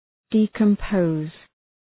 Προφορά
{,di:kəm’pəʋz}